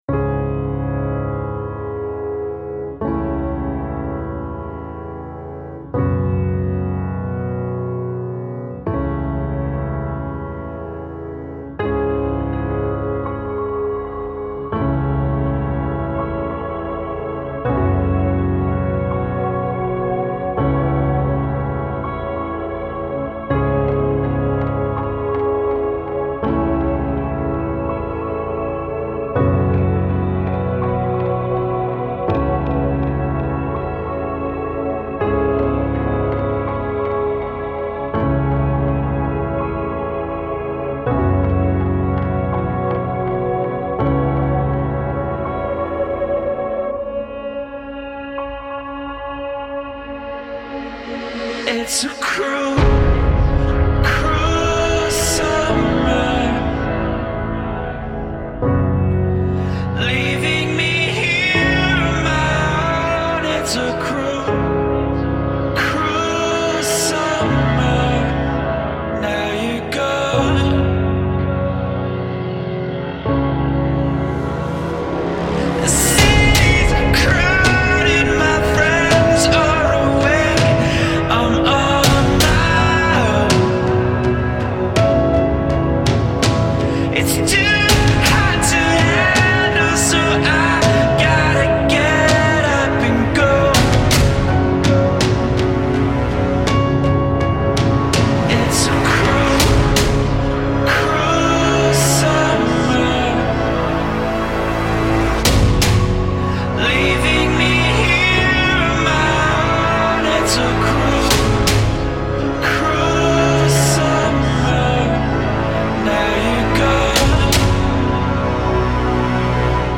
la banda metalera